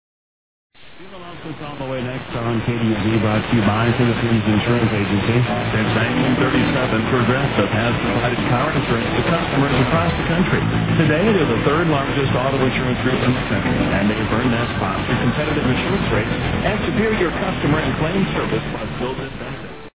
This page contains DX Clips from the 2010 DX season!
KDUZ Hutchinson, MN 1260 heard at 6:42am.